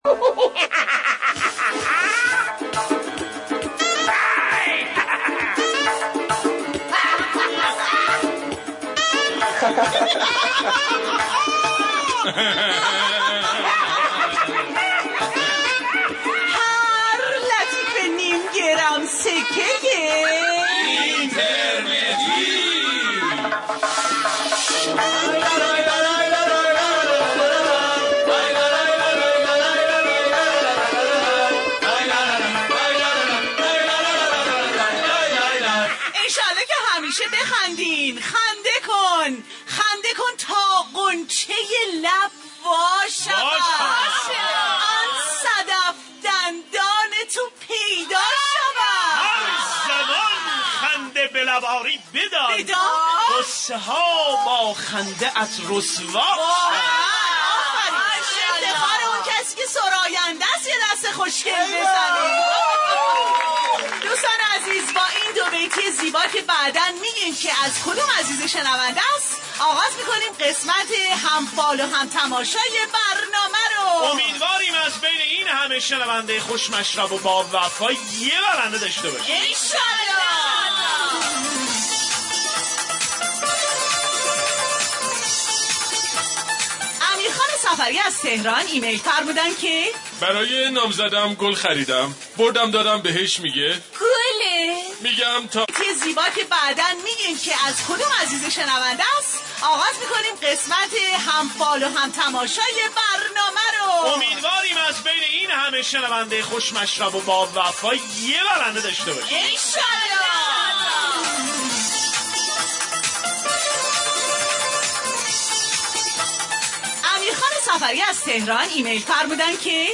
برنامه طنز جمعه ایرانی